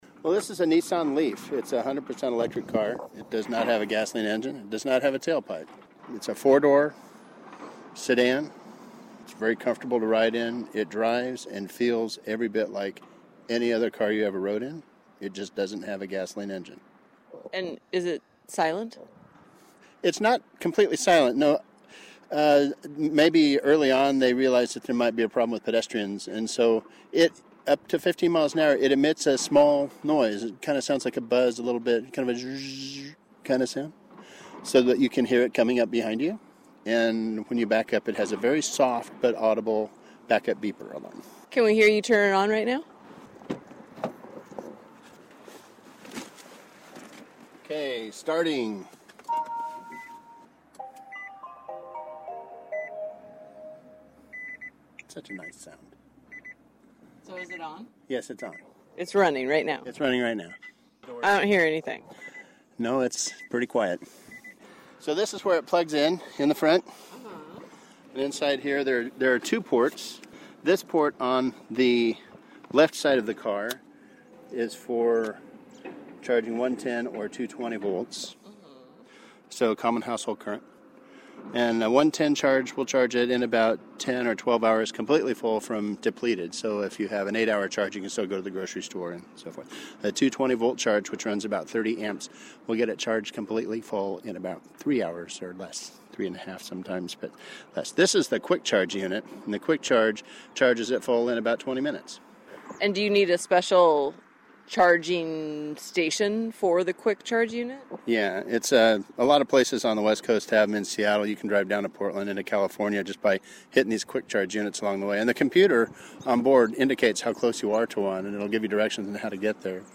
Below is an audio postcard of their brief jaunt around town.